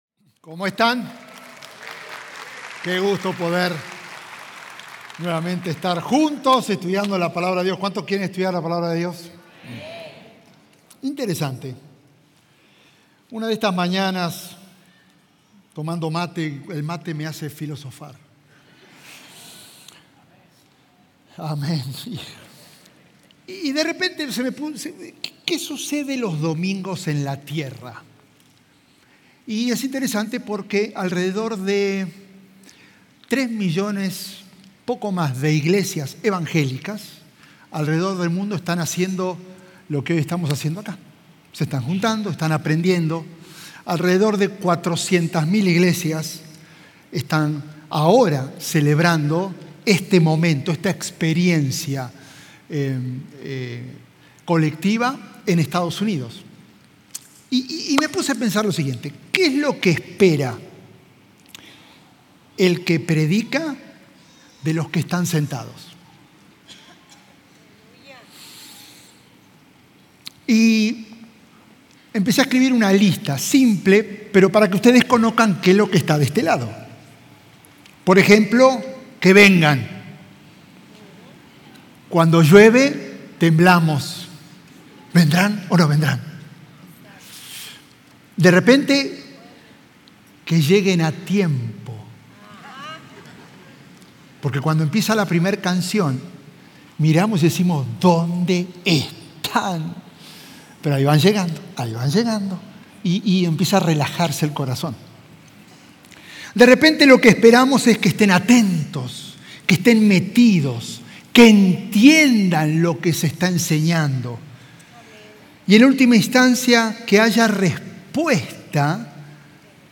Sermones Dominicales – Media Player